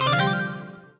collect_item_jingle_01.wav